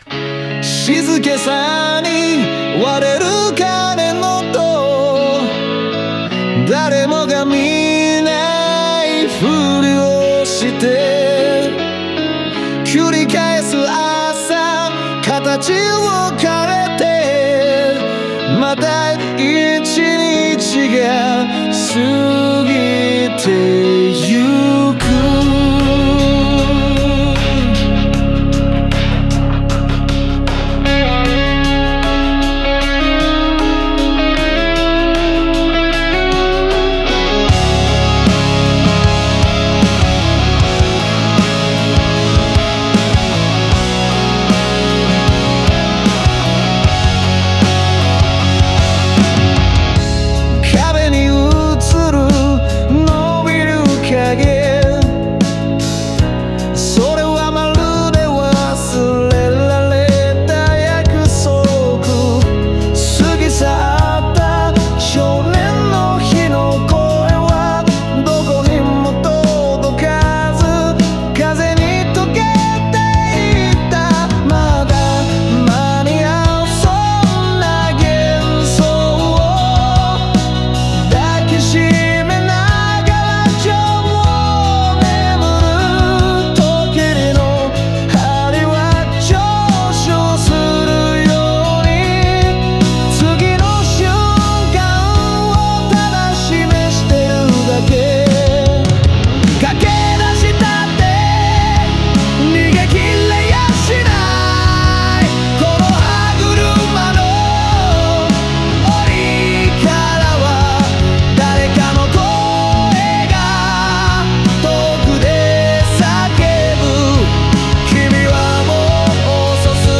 オリジナル曲♪